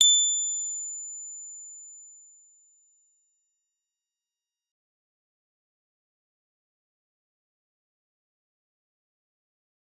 G_Musicbox-A7-f.wav